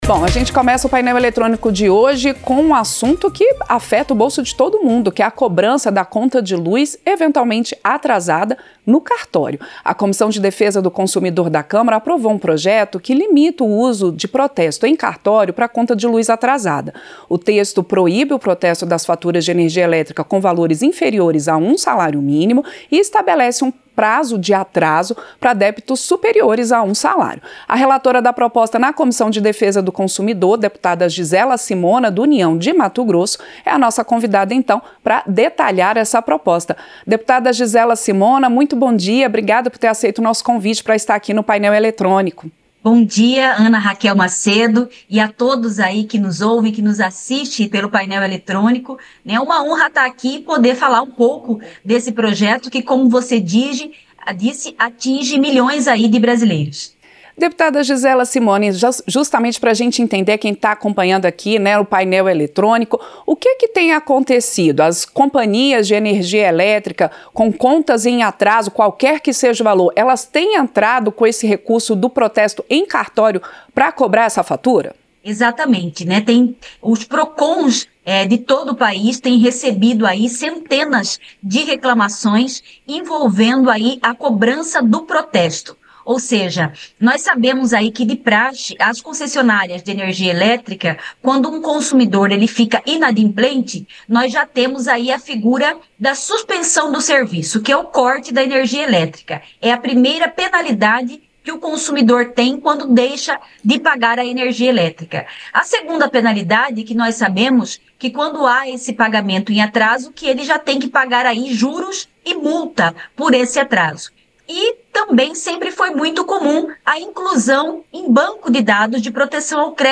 Entrevista - Dep. Gisela Simona (União-MT)